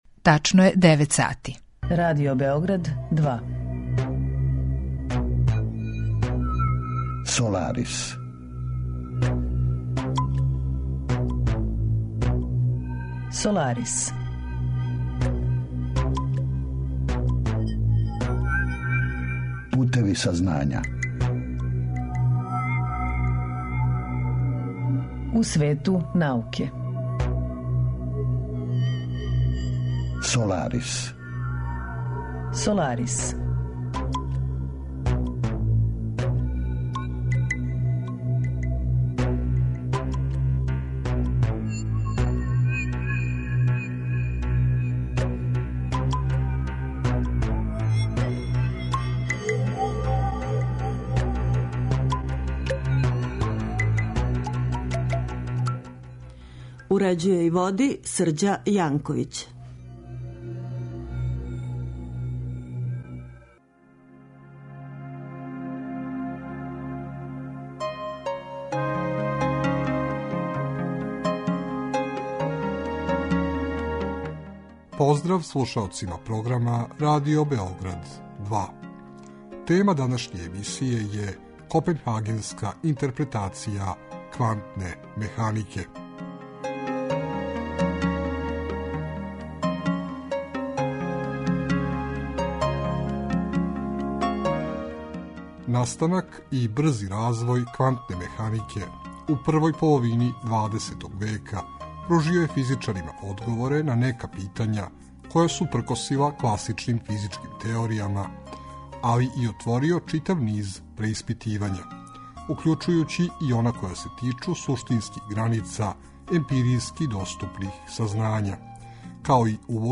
Разговор је први пут емитован 18. фебруара 2015.